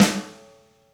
• Long Room Reverb Acoustic Snare Sound G Key 432.wav
Royality free snare drum sample tuned to the G note. Loudest frequency: 1722Hz
long-room-reverb-acoustic-snare-sound-g-key-432-BRH.wav